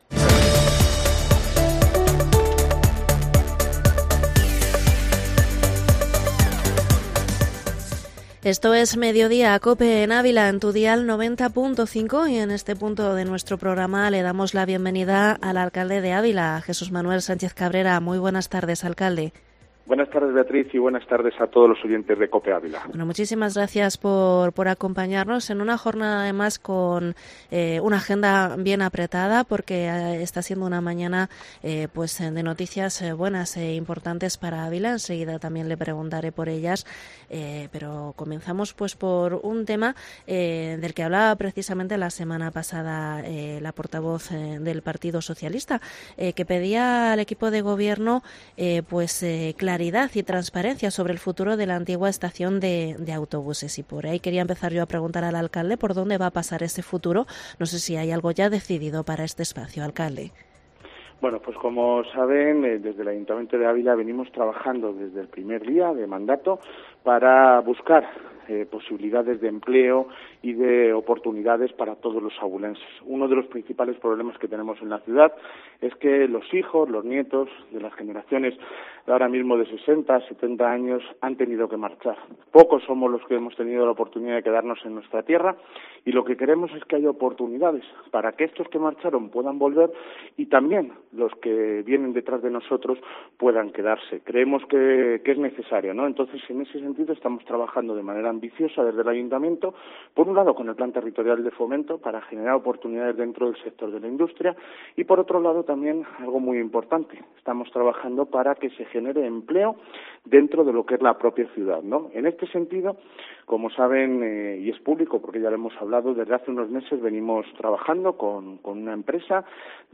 Entrevista alcalde de Ávila en Mediodía Cope Ávila 04/10/2021